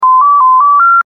Computer Beep 04
Computer_beep_04.mp3